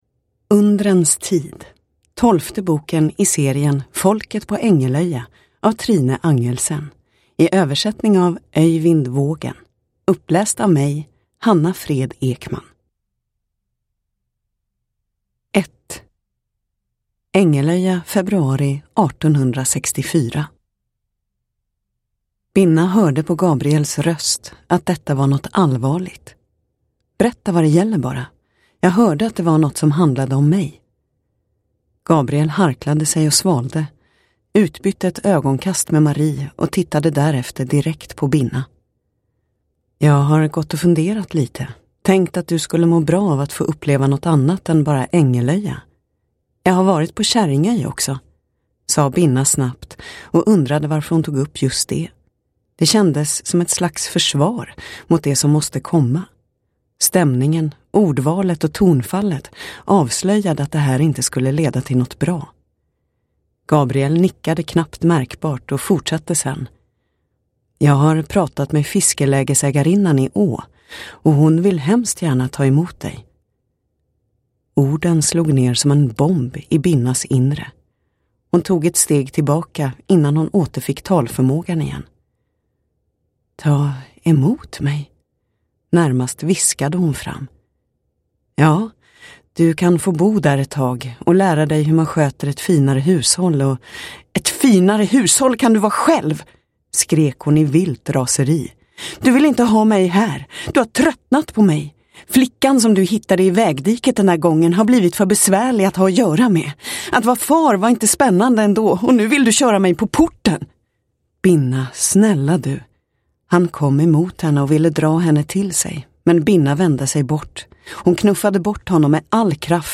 Undrens tid – Ljudbok – Laddas ner